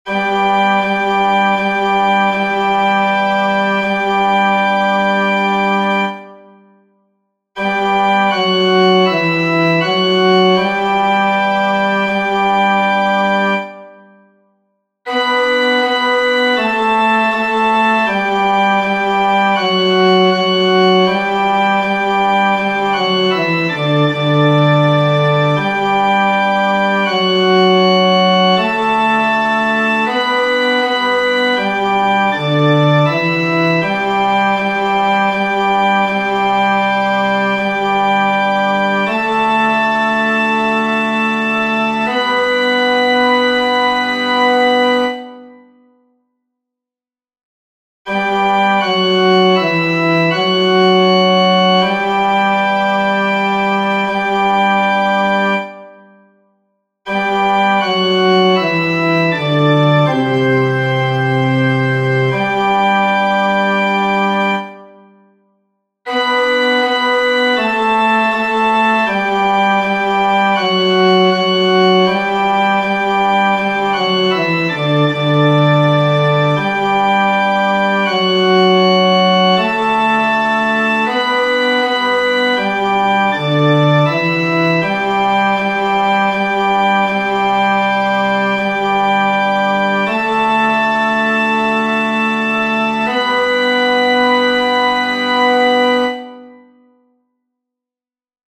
FF:VH_15b Collegium musicum - mužský sbor, FF:HV_15b Collegium musicum - mužský sbor
Laska_opravdiva-Bar.mp3